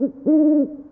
Ambient sound effects